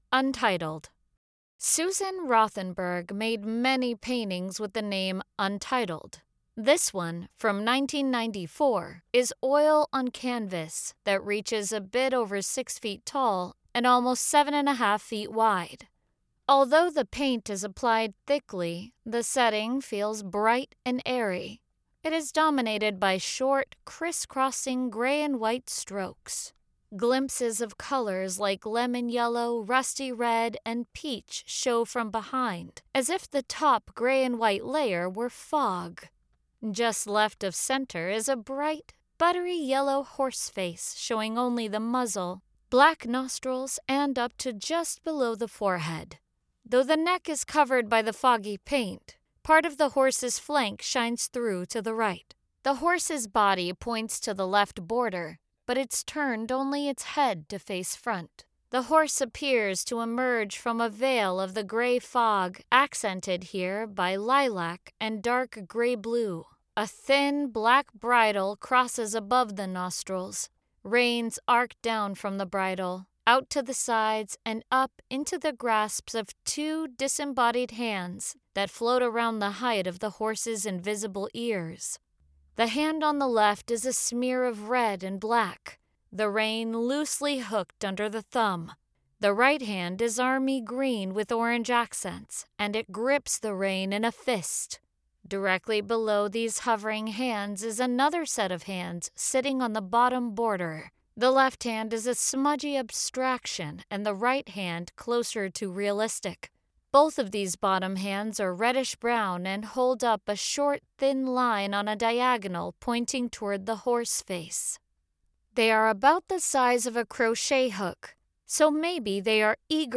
Audio Description (02:08)